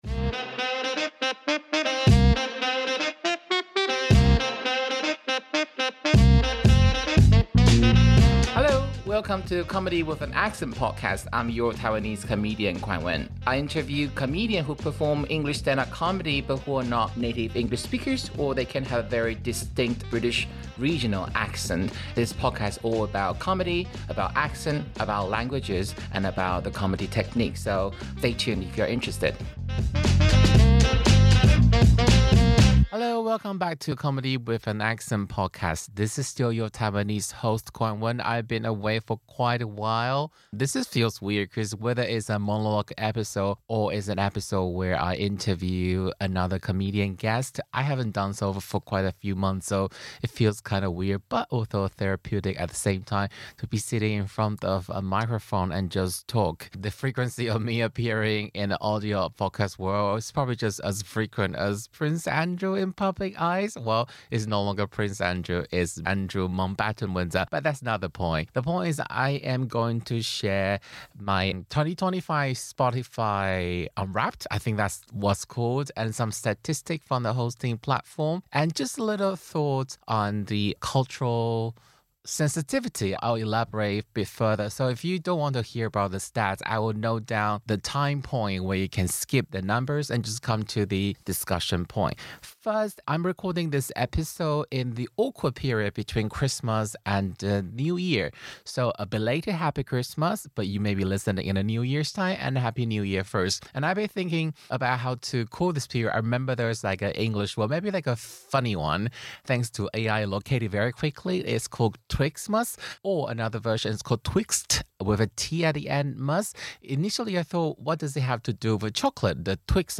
Tommy Robinson Rally 40:10 Play Pause 1h ago 40:10 Play Pause Play later Play later Lists Like Liked 40:10 A year end review based on 2025 Spotify Unwrapped and the podcast platform statistics, released on the penultimate day of 2025! In case you are not a stats nerd on where the podcast is consumed, this monologue episode also includes a brief discussion on the Bennet scale, also called the Developmental Model of Intercultural Sensitivity.